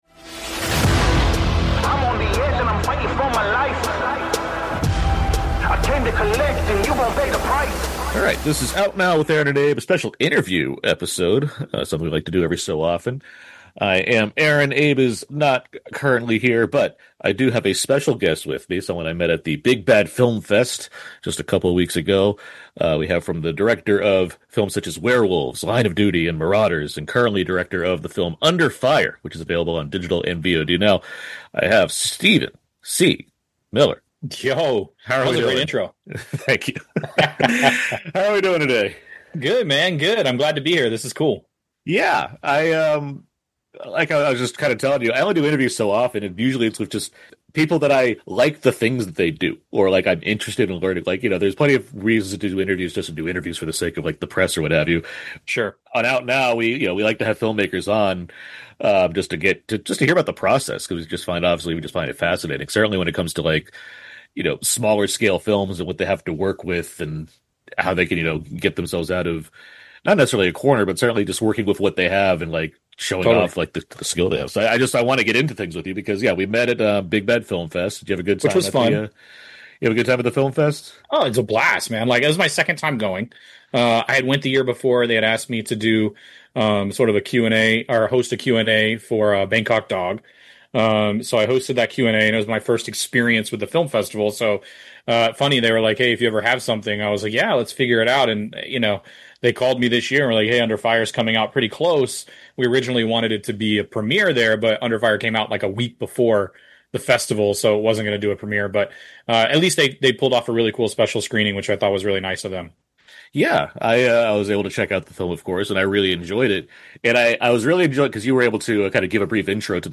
Listen in to this special interview episode